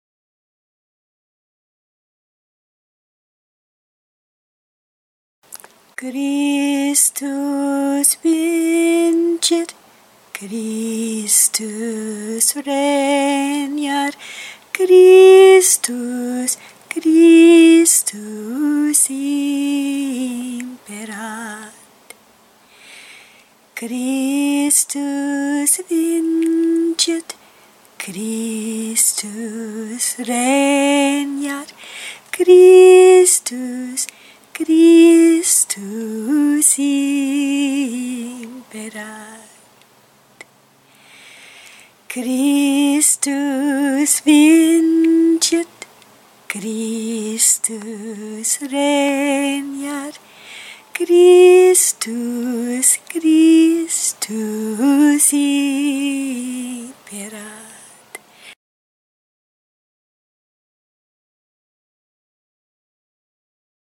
CHRISTUS VINCIT CHANT
christus-vincit-song.mp3